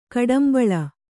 ♪ kaḍambaḷa